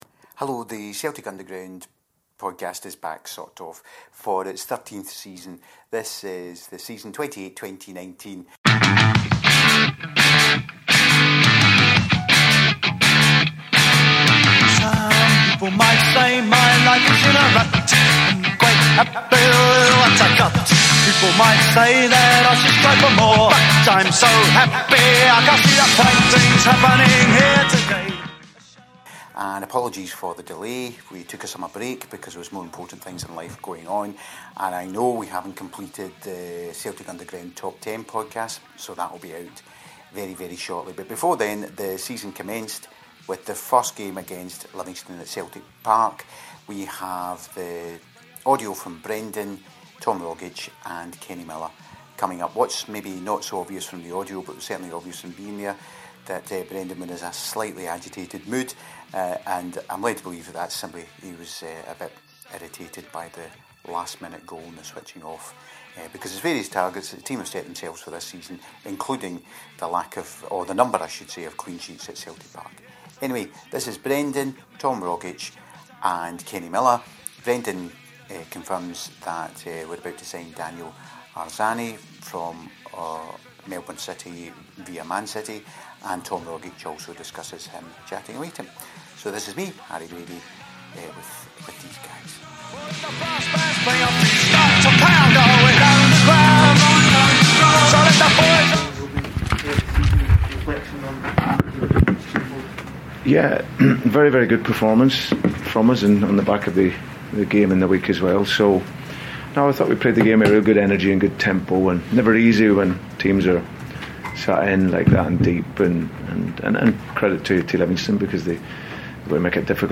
Podcast Xtra - The Post Livi Interviews